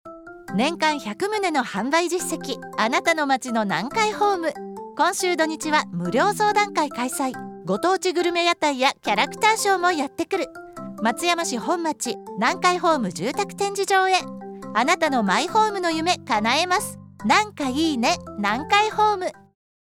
CMサンプル その１（オープン告知篇）
南海放送スタジオにてアナウンサーのナレーション録りと編集